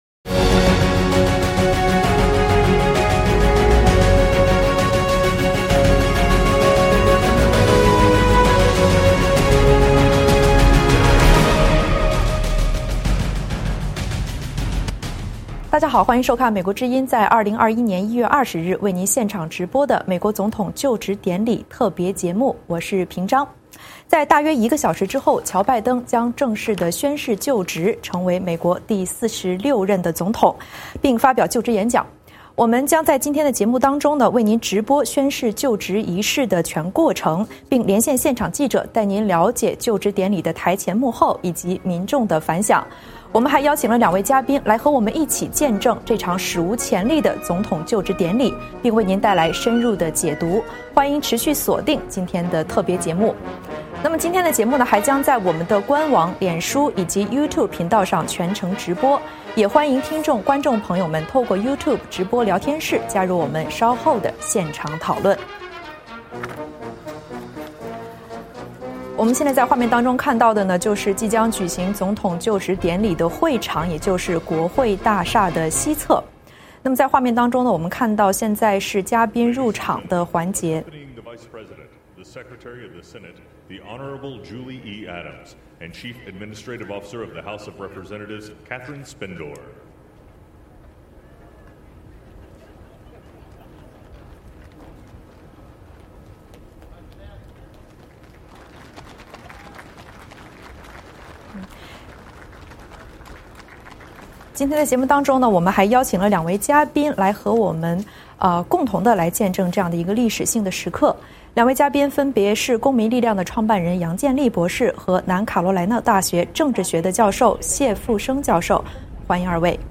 在两个小时的特别节目中，我们为您全程现场直播就职仪式，并同声传译拜登的就职演讲。我们还连线现场记者，带您观摩就职典礼的台前幕后，了解美国民众的反响。同时，我们邀请多位嘉宾全方位解析这场史无前例的总统就职典礼背后所蕴含的深意，以及所预示的美国未来的走向。